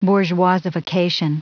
Prononciation du mot bourgeoisification en anglais (fichier audio)
Prononciation du mot : bourgeoisification